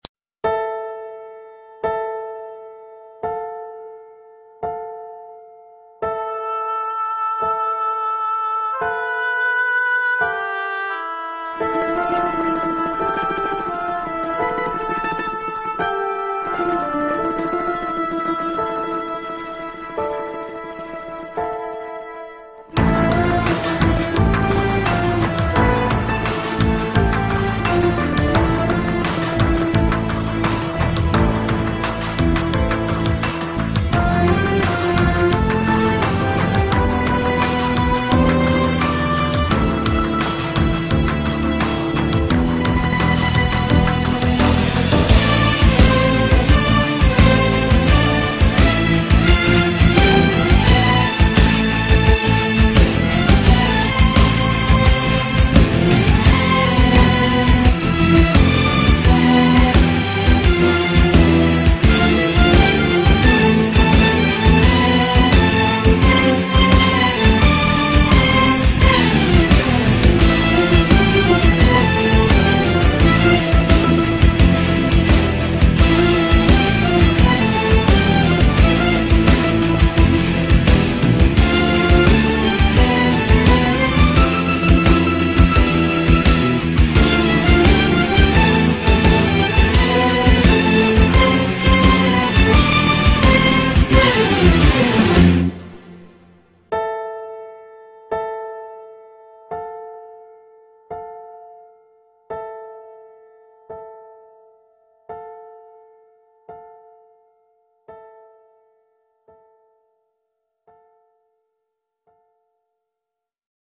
آهنگ بی کلام , تیتراژ فیلم و سریال